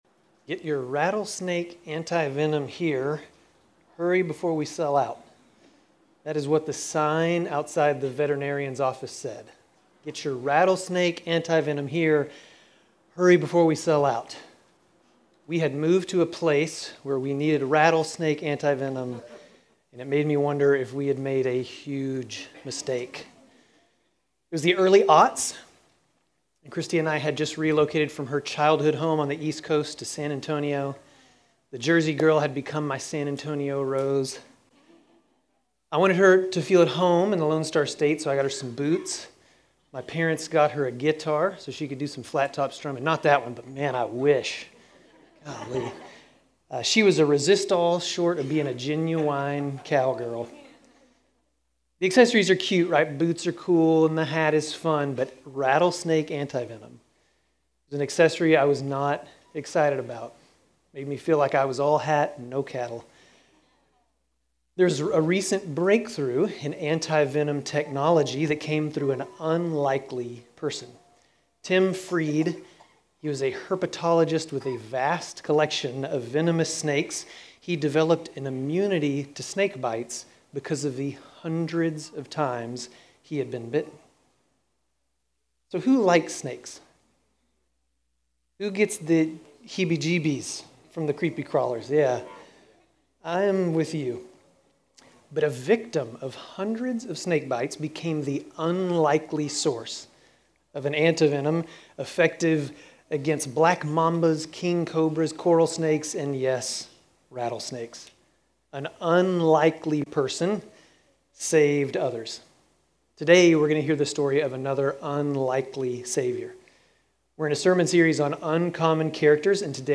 Grace Community Church Dover Campus Sermons 7_27 Dover campus Jul 28 2025 | 00:25:08 1x 00:00 / 00:25:08 Subscribe Share RSS Feed Share Link Embed